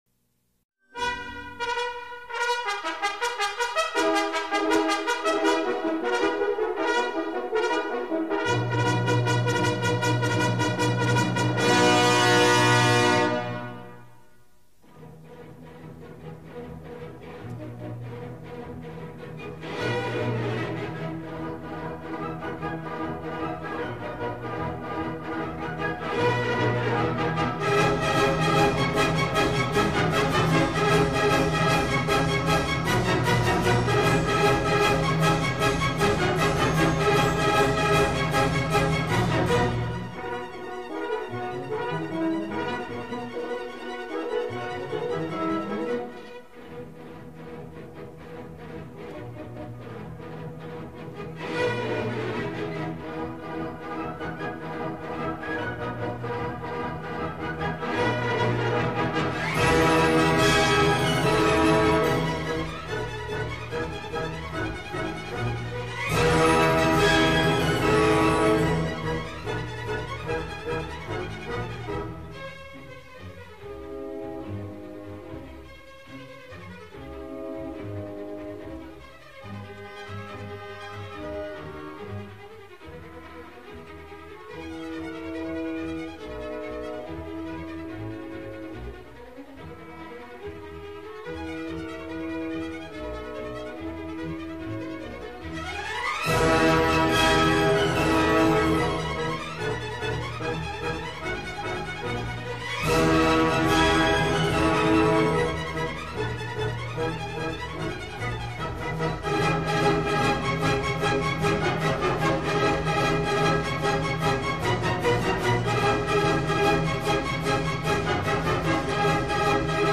william-tell-and-the-rhythm-sticks.dv_.mp3